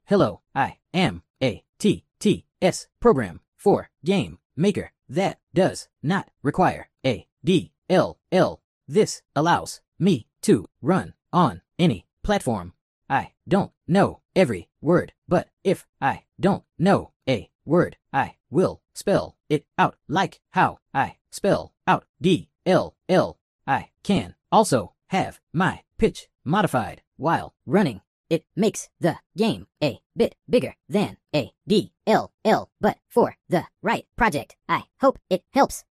Working on a TTS solution for game maker that doesn't require a DLL.
This is it "Working" but I still have some tweaking to do!